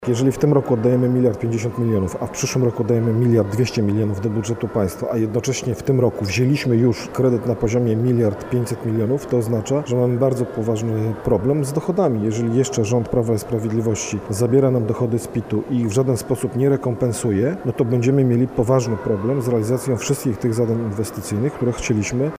Miasto ma coraz mniej pieniędzy, a to jest związane z polityką podatkową – zaznacza wiceprzewodniczący Rady Warszawy Sławomir Potapowicz z Koalicji Obywatelskiej.